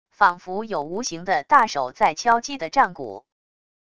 仿佛有无形的大手在敲击的战鼓wav音频